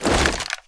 bash2.wav